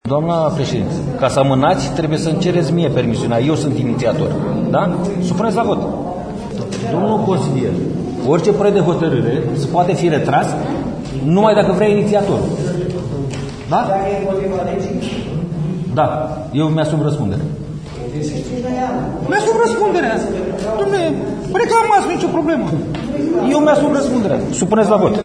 Iniţiatorul proiectului de hotărâre, primarul Constantin Sava, a refuzat din start ideea rediscutării acestuia într-o şedinţă viitoare: